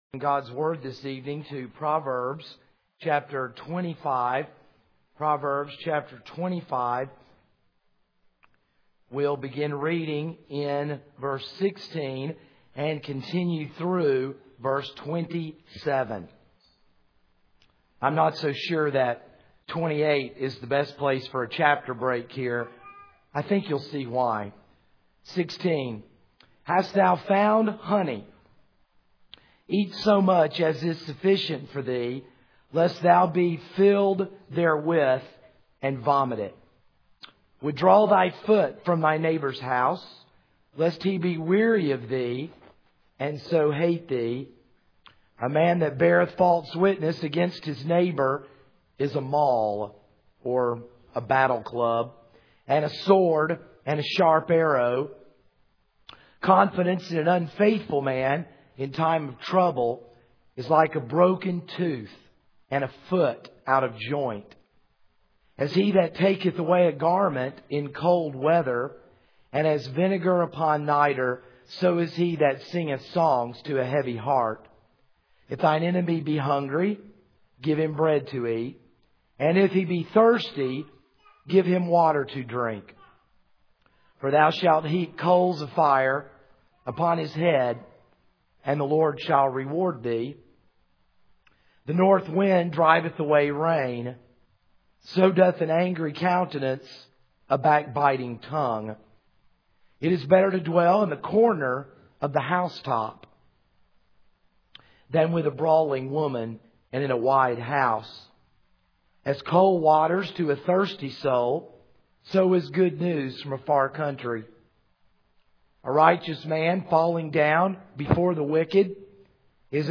This is a sermon on Proverbs 25:16-27.